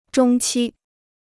中期 (zhōng qī): 중기; 중반.